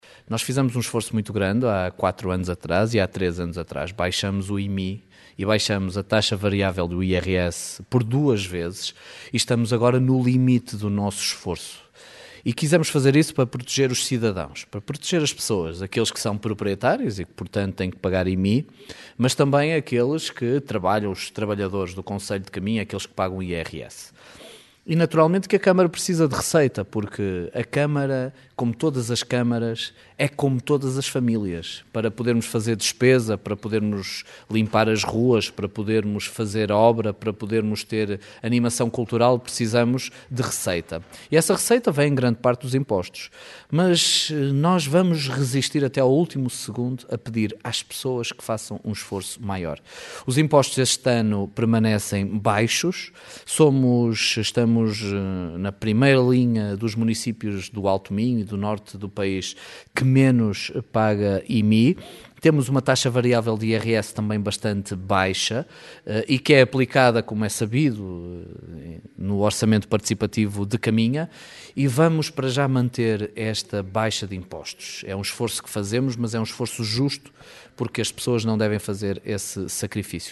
Em declarações à Rádio Caminha, o presidente do executivo sublinha o esforço que tem vindo a ser feito pela Câmara no sentido de manter os impostos em valor baixos.